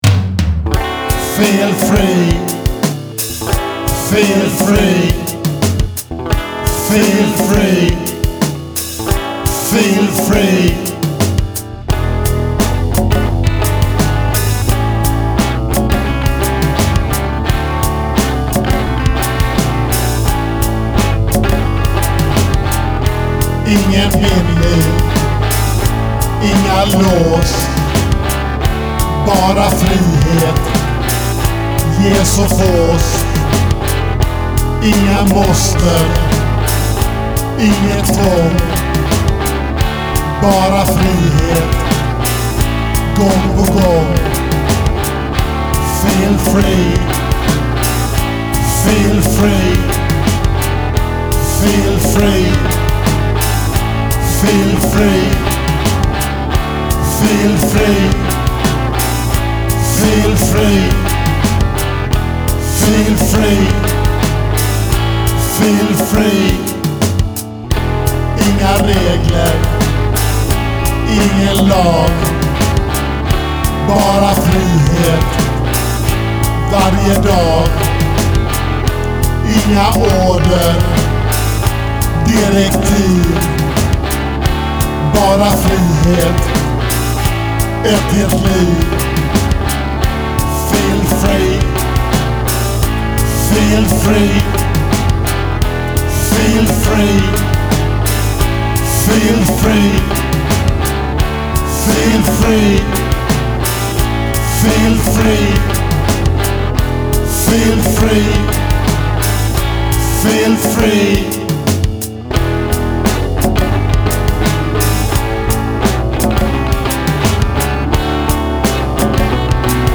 E7 (D) A7 (C#) D7 (C) A7 (C#)